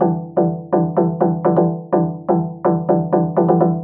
cch_synth_resonate_125_F#m.wav